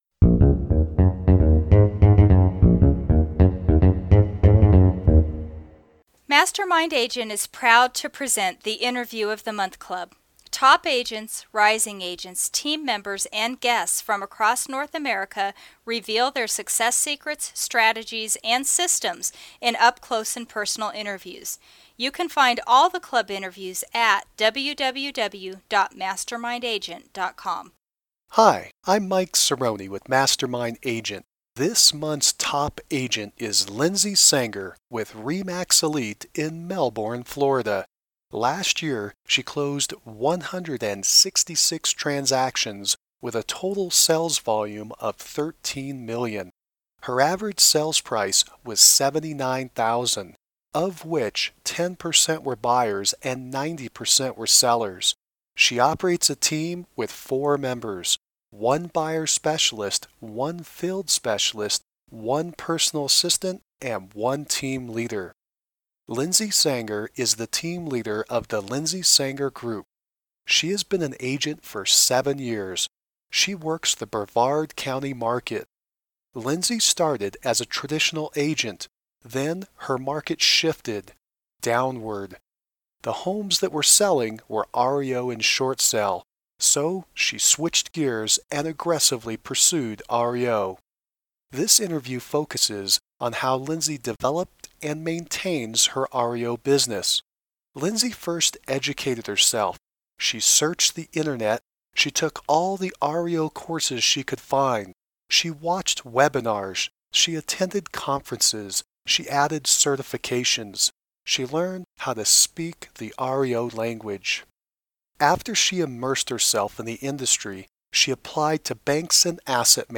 March 21, 2012 Top Agent Interview with